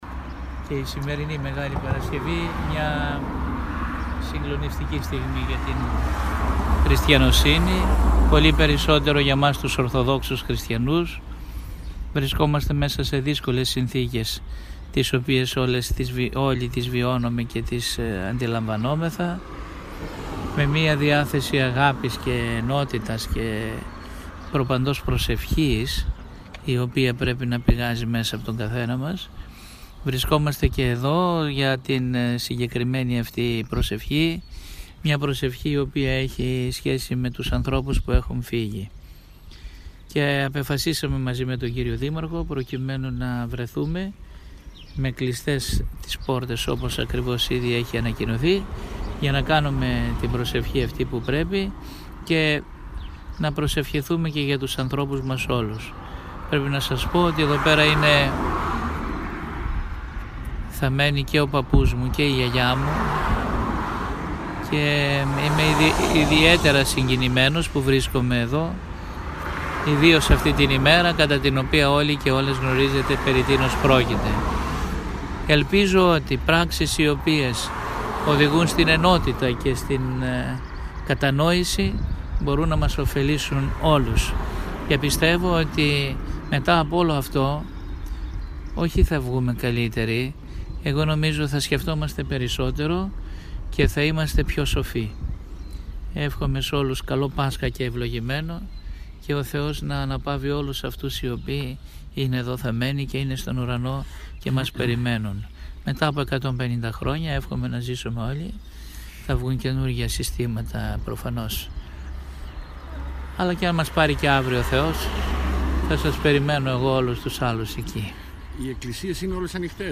Ο Μητροπολίτης Φιλίππων Νεαπόλεως Θάσου Στέφανος βρίσκεται στην εκκλησία των Αγίων Θεοδώρων, μαζί με το Δήμαρχο και τον Αντιπεριφερειάρχη.
Η δήλωση του Μητροπολίτη επισυνάπτεται ενώ οι φωτογραφίες δείχνουν την κατάσταση που επικρατούσε μέχρι και πριν λίγο σε εκκλησίες της πόλης :